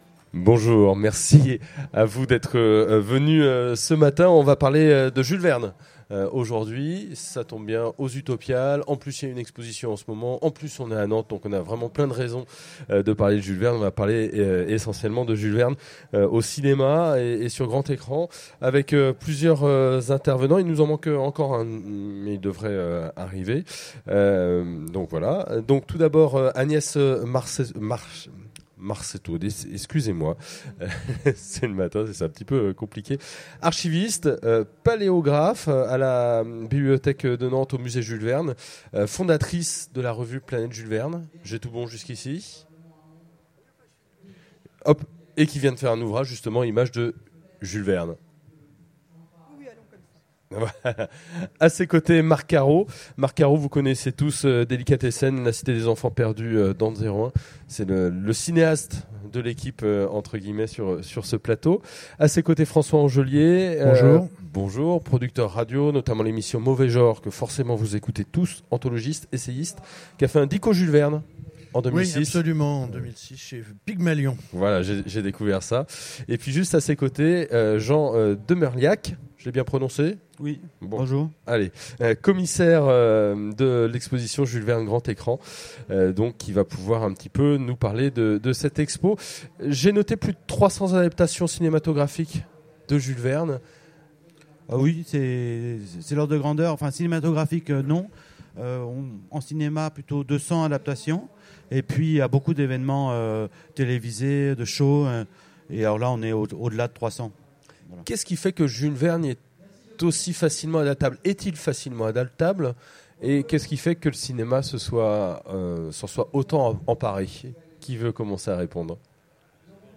Utopiales 2015 : Conférence Jules Verne Grand écran